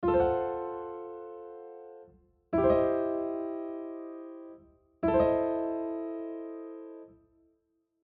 Split Chords
Those changes are more common with thirds but also may happen to the 5th, 7th or the tonic – the altered chord members are usually separated by an octave:
split chords.mp3